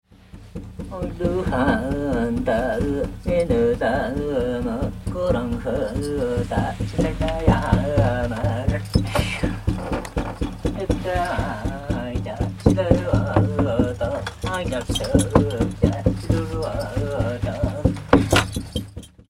Tsaatan Shaman Ceremony from the East Taiga, Mongolia
We were granted rare permission to record on location in the remote northern region of Mongolia a shamanic healing ceremony.  A male shaman of the Dukha nomadic reindeer herders chants and plays his drum while deep in trance.